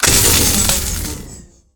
overheat.ogg